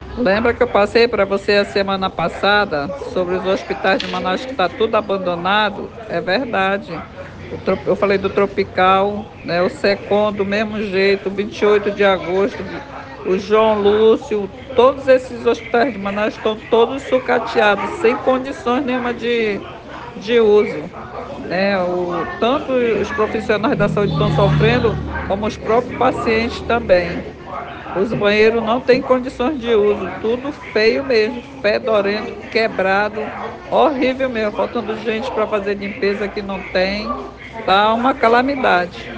Aos gritos, funcionários dizem que a Fundação de Medicina Tropical foi sucateada pelo governador Wilson Lima
Na íntegra, a fala da funcionária indignada pelo abandono da FMT: